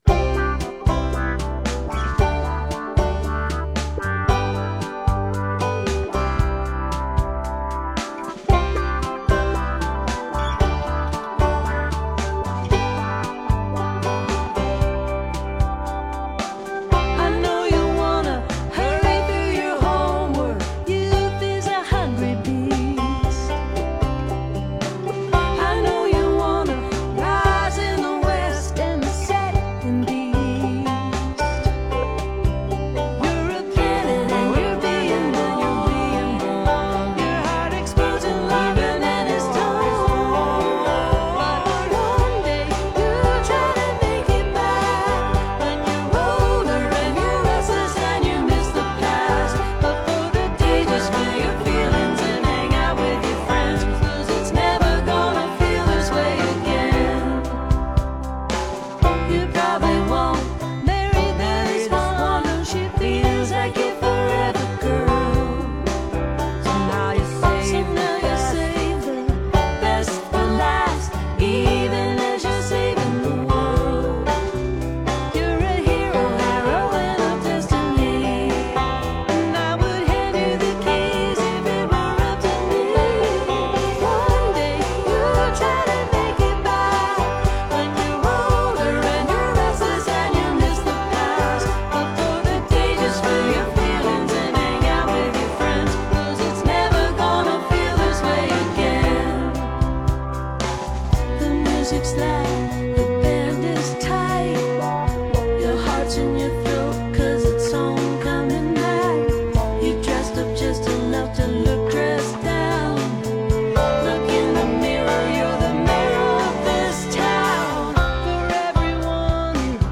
(captured from the web broadcast)